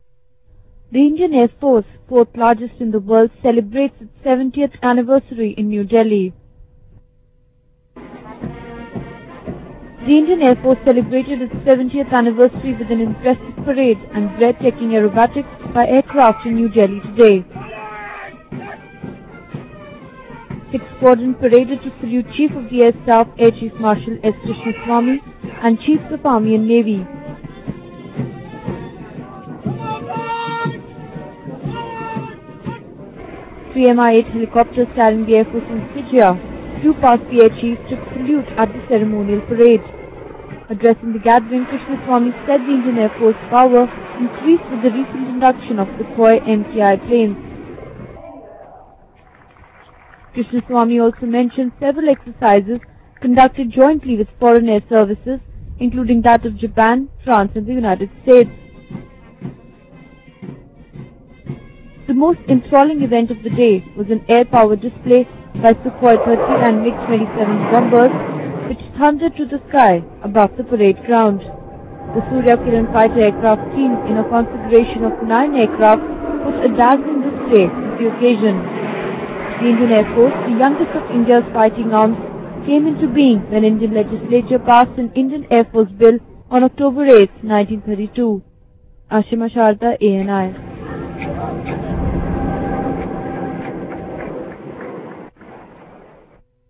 Suryakiran aircraft perform aerobatics as part of the Air Force Day celebrations in New Delhi on Tuesday.